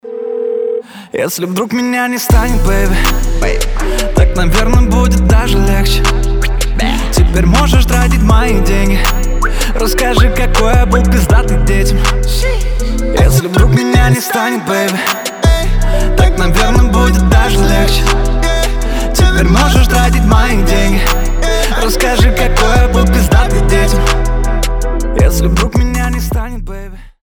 • Качество: 320, Stereo
Хип-хоп
дуэт